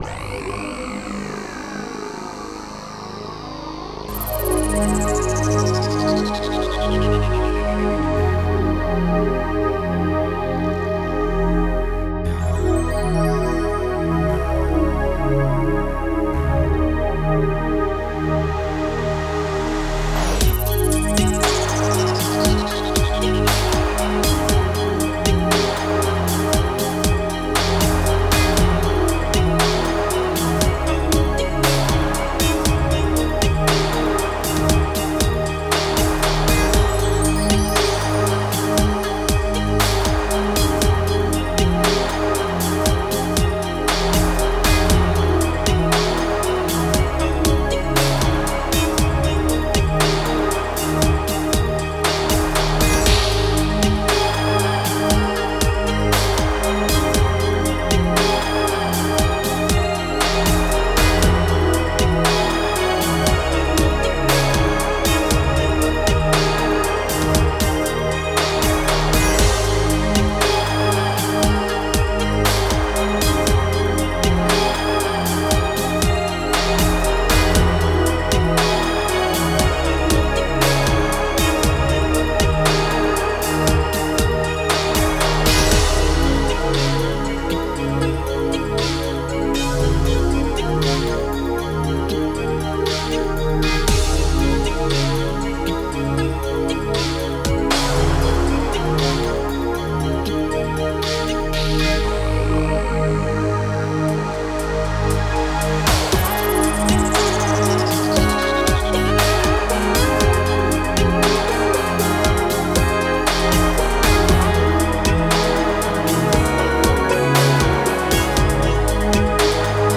Synthpop Ballad
A dreamy synthpop ballad
The chords are just so, so good.